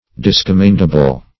Search Result for " discommendable" : The Collaborative International Dictionary of English v.0.48: Discommendable \Dis`com*mend"a*ble\ (d[i^]s`k[o^]m*m[e^]nd"[.a]*b'l), a. Deserving, disapprobation or blame.
discommendable.mp3